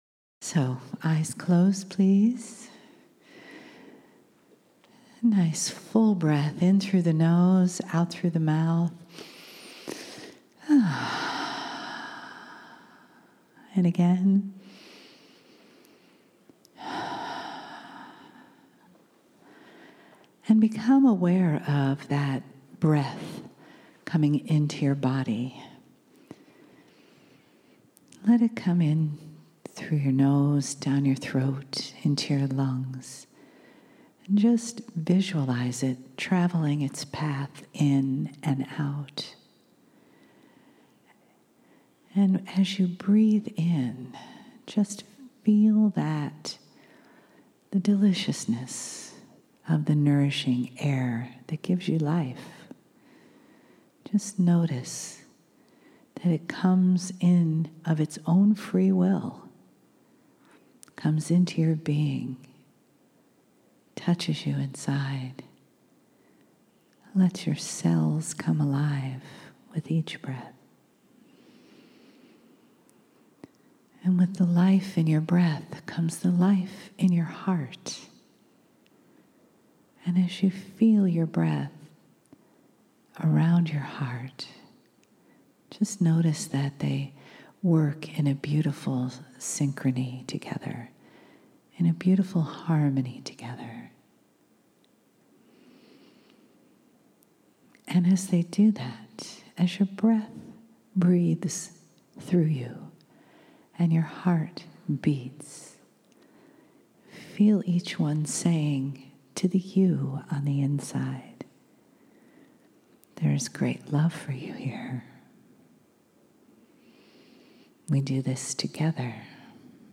Free download - Regina, SK, Canada - April 1 & 2, 2017
KRYON CHANNELLING